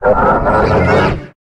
Cri de Hoopa Déchaîné dans Pokémon HOME.
Cri_0720_Déchaîné_HOME.ogg